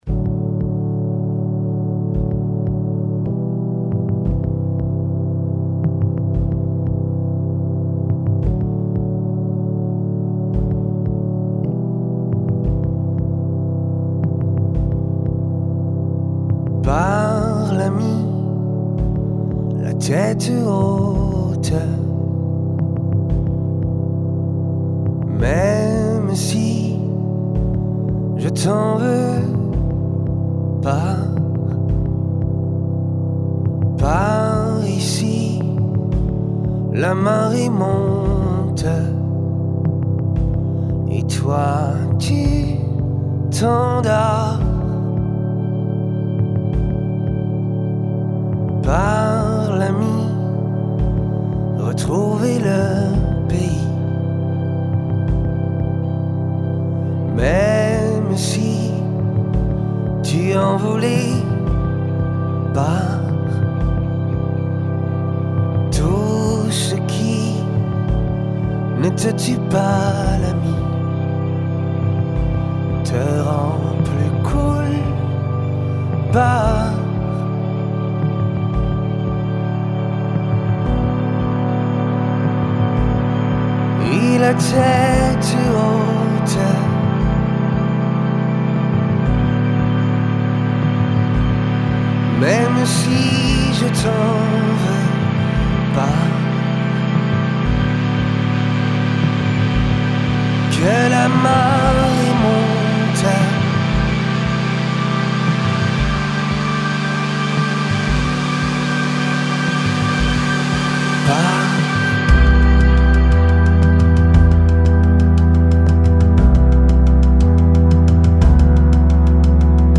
trio montpellierain